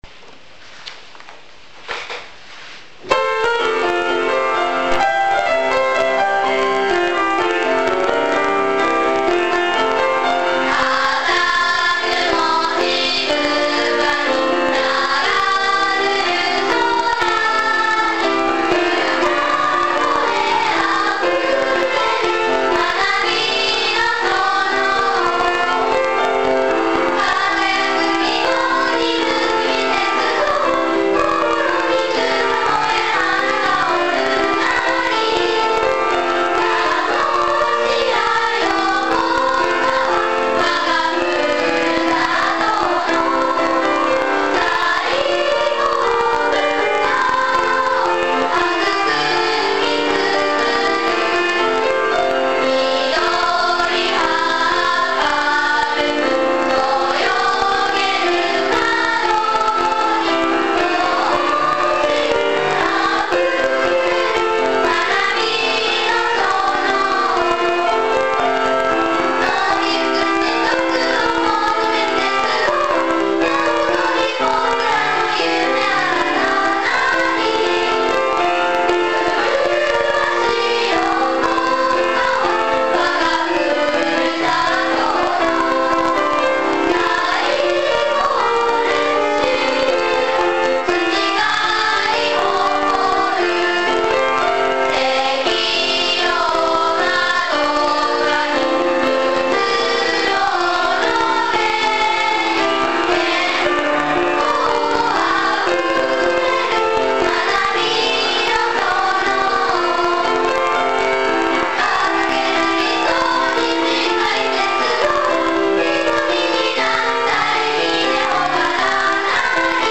♪　児童の歌声が聞けます。♪